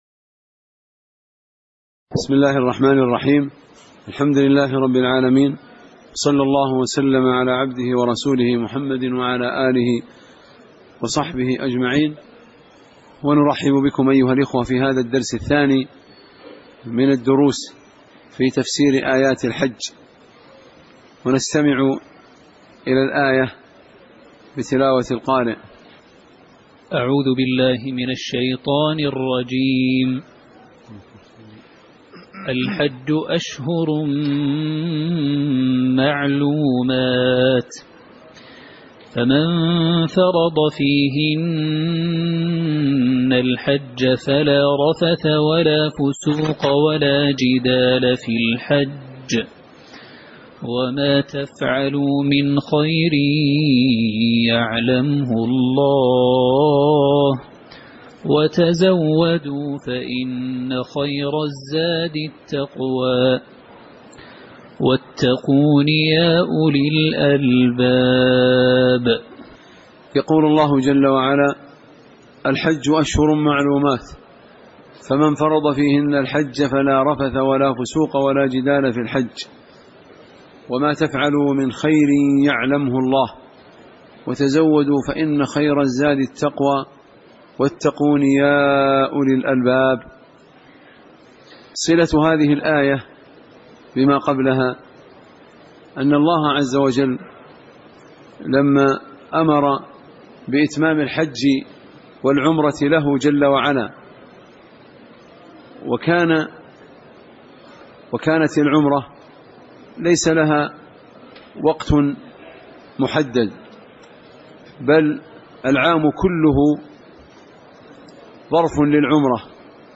تاريخ النشر ٢١ ذو القعدة ١٤٣٨ هـ المكان: المسجد النبوي الشيخ